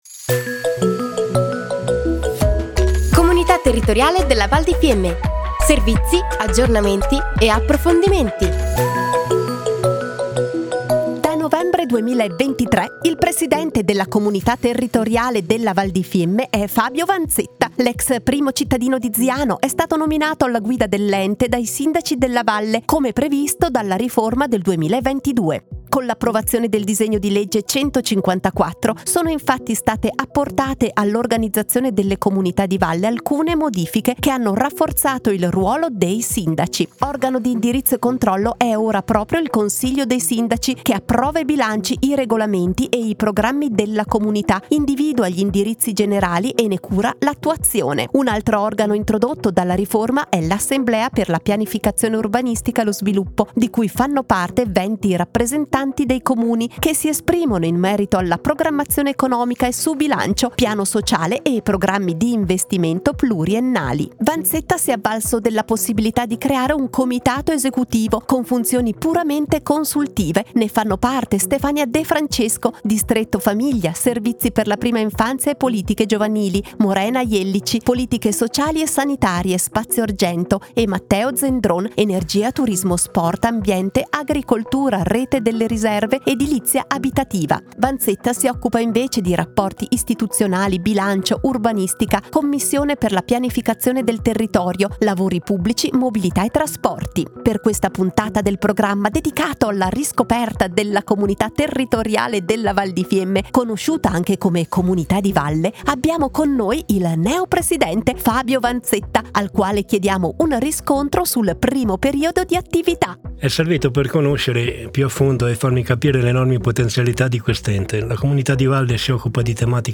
Puntata 01 - Presentazione, impressioni e priorità del presidente Fabio Vanzetta / Anno 2024 / Interviste / La Comunità si presenta tramite Radio Fiemme / Aree Tematiche / Comunità Territoriale della Val di Fiemme - Comunità Territoriale della Val di Fiemme